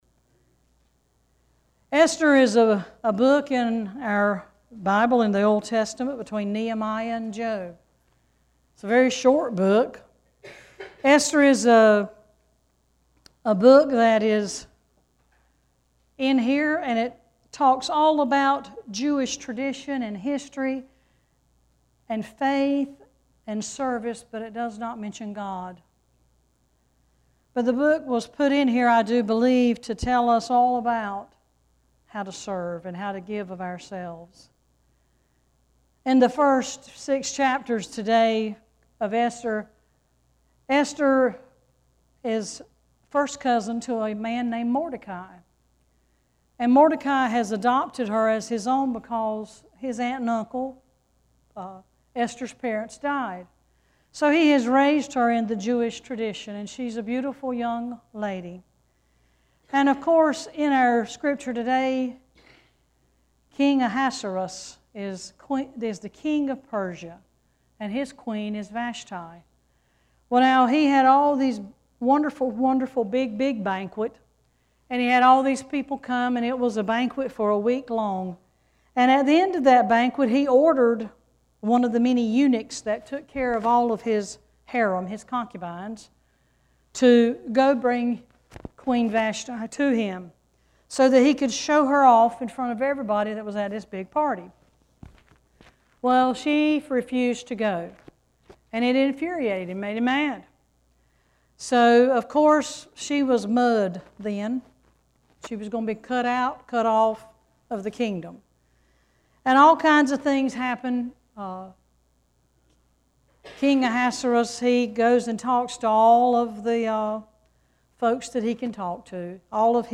9-30-18-sermon.mp3